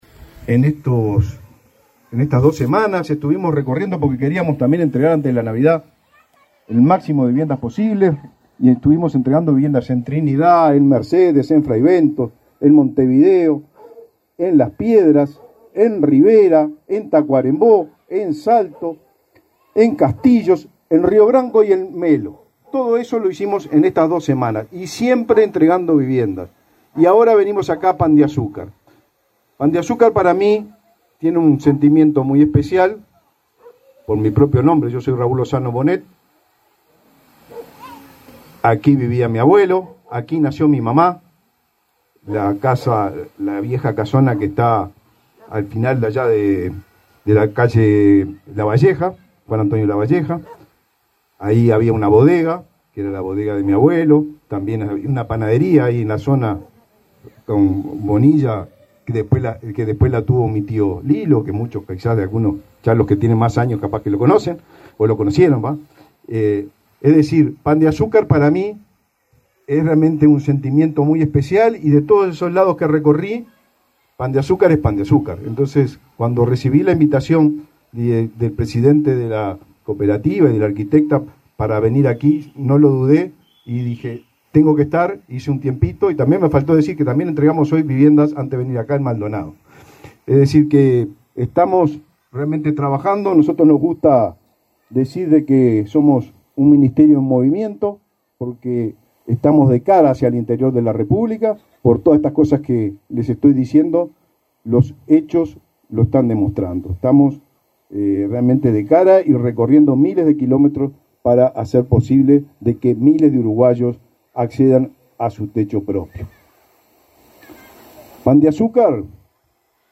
Palabras del ministro del MVOT, Raúl Lozano, en Pan de Azúcar
El titular del Ministerio de Vivienda y Ordenamiento Territorial (MVOT), Raúl Lozano, participó en la inauguración de 44 viviendas en cooperativa Covi